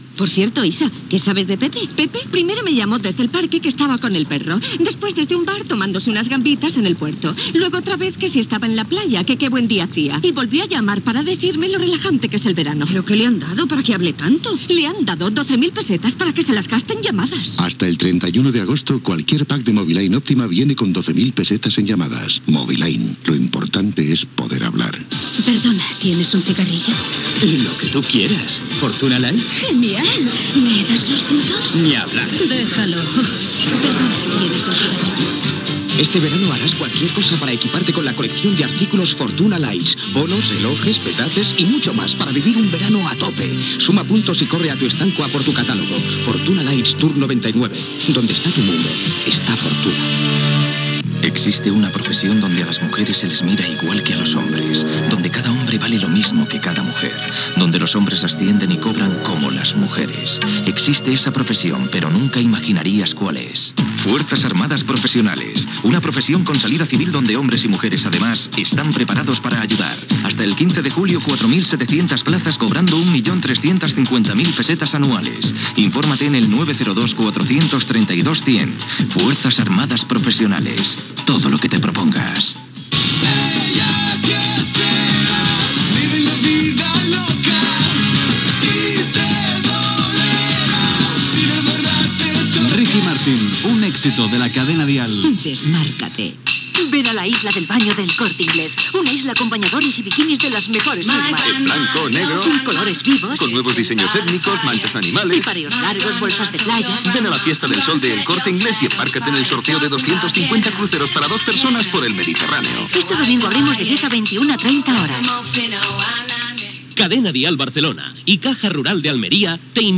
Falques de publicitat i promoció del festival "El Día de Dial" a Barcelona, hora, identificació de l'emissora.
FM